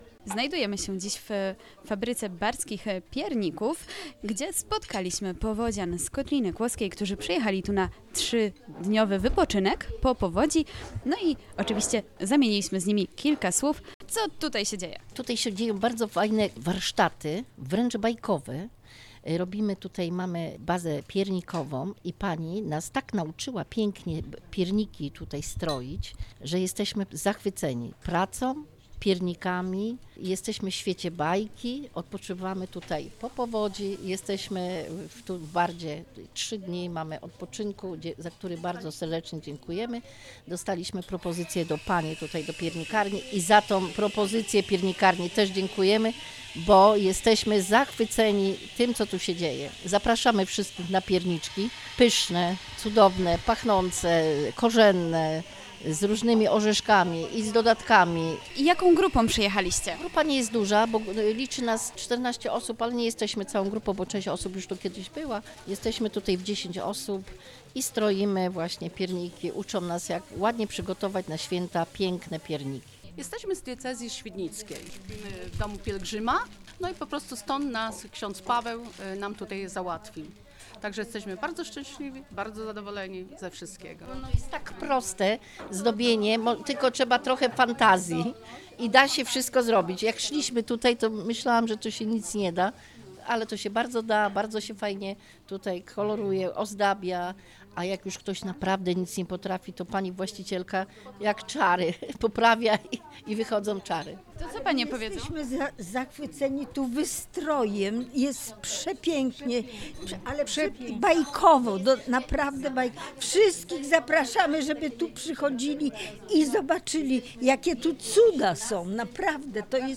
Reportaz-z-powodzianami.mp3